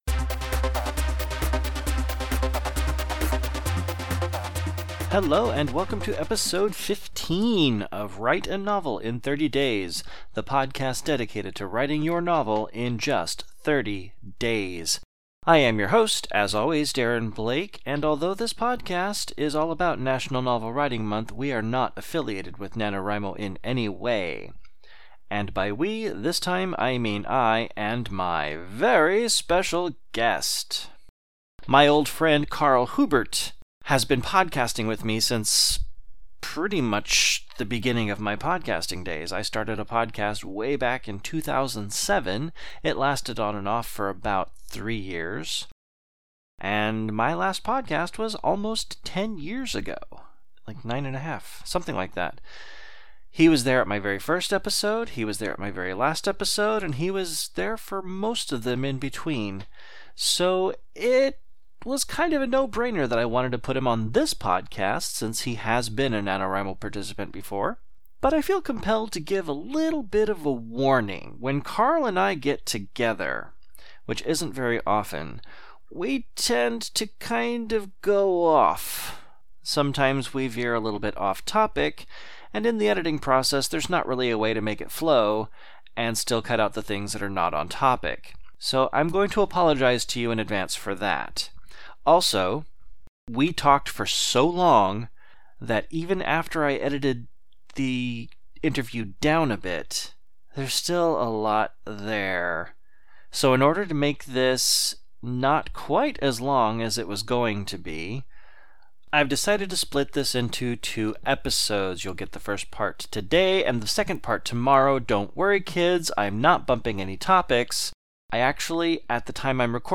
It’s another interview episode.